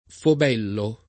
[ fob $ llo ]